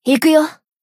贡献 ） 分类:蔚蓝档案语音 协议:Copyright 您不可以覆盖此文件。
BA_V_Kayoko_Battle_Shout_2.ogg